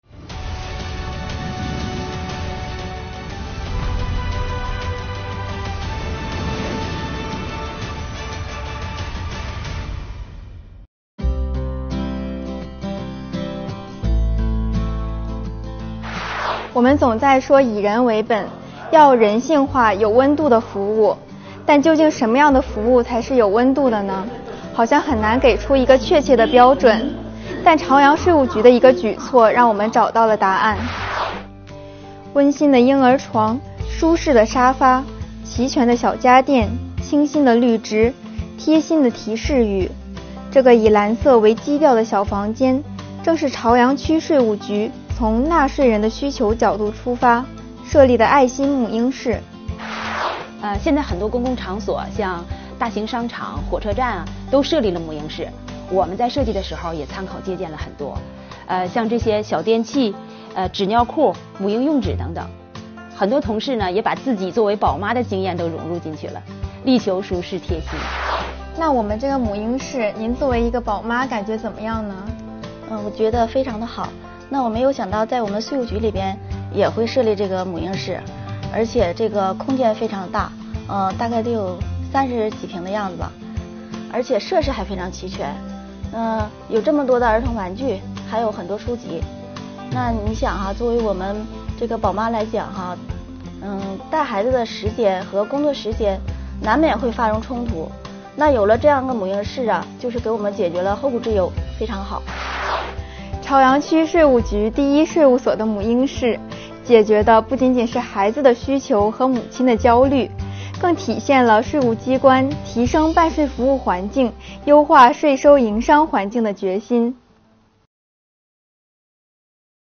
“长春税务春风税语云直播”（2023年第2期）直播回放